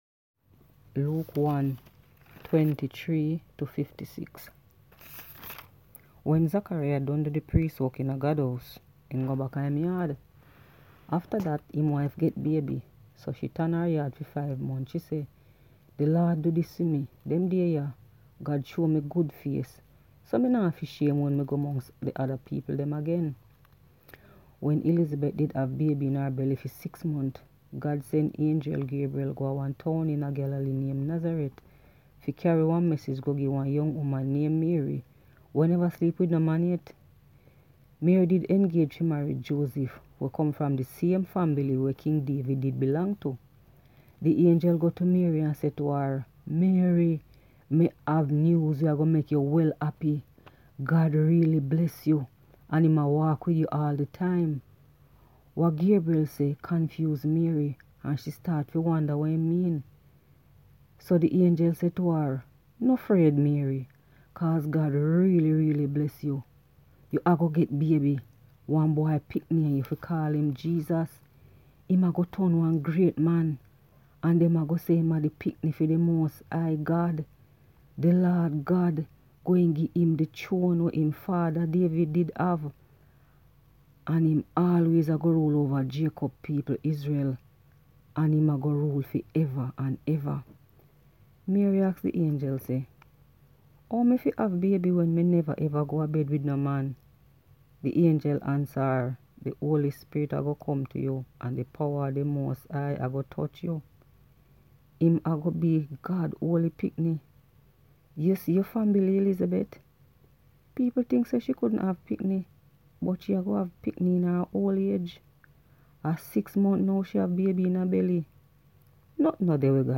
Enjoy scripture reading from Di Jamiekan Nyuu Testiment